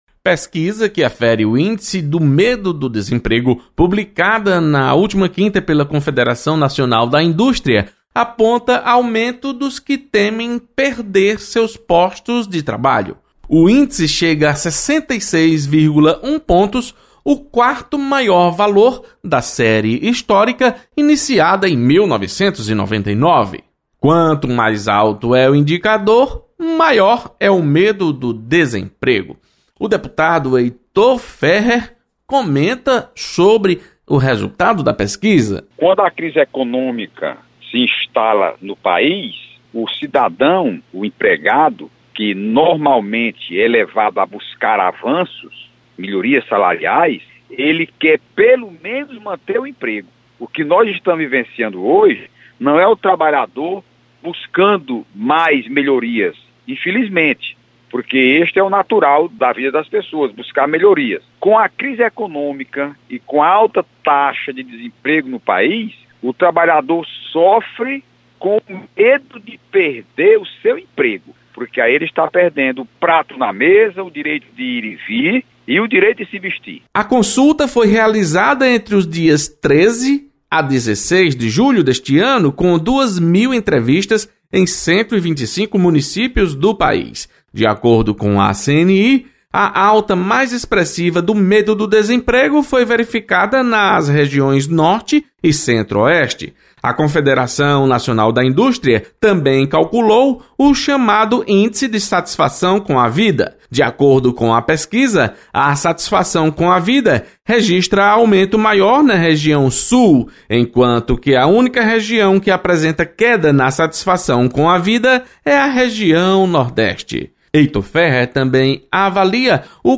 Deputado Heitor Férrer analisa temos pela perda do emprego. Repórter